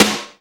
SNARE GATE03.wav